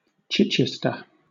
Chichester (/ˈɪɪstər/
CHITCH-ist-ər)[4] is a cathedral city and civil parish in the Chichester district of West Sussex, England.[5] It is the only city in West Sussex and is its county town.